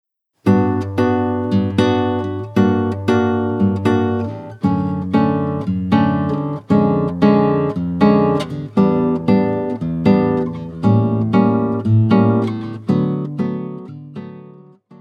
violão de 6 cordas